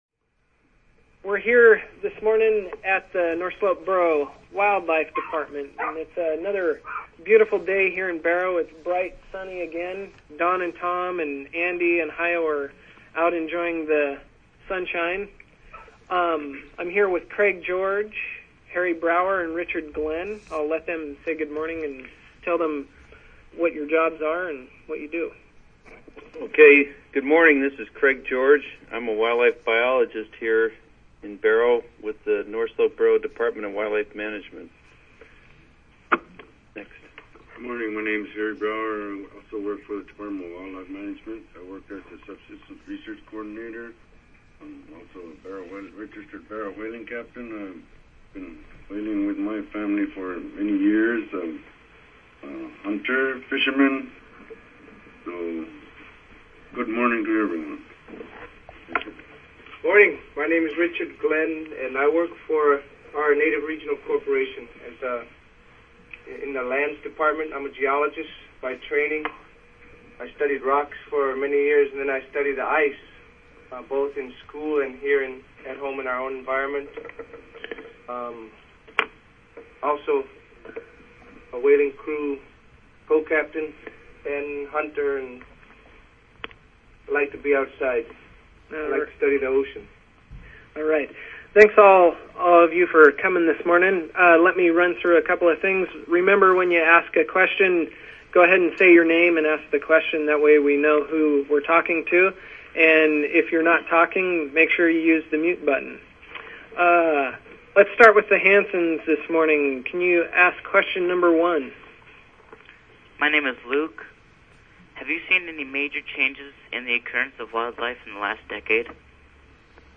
Day 3 Summary - Audio Conference